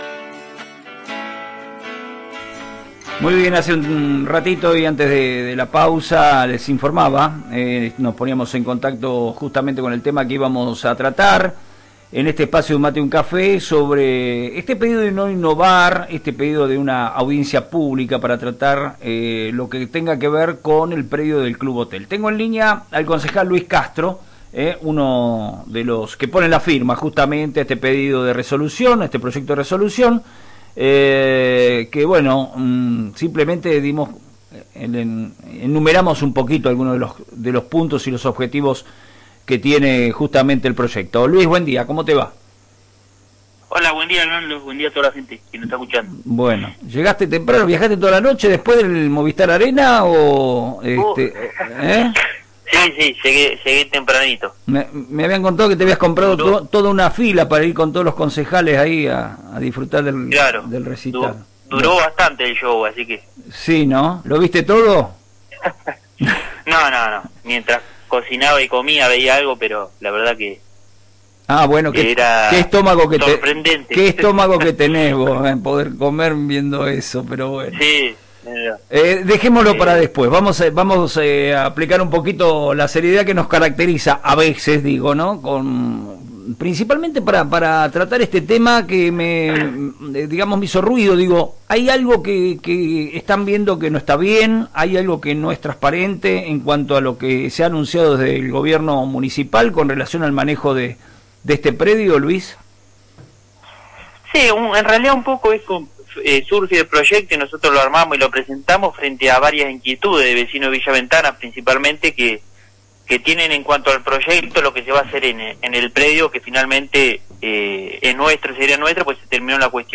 Luis Castro, concejal por el bloque de Juntos por Tornquist – UCR, explica en FM Reflejos que la solicitud al Departamento Ejecutivo a que aplique una medida de no innovar hasta que se formalice el Proyecto de Revalorización del predio del Club Hotel de la Ventana yace de las inquietudes de varios vecinos sobre algunas cuestiones de mencionado proyecto.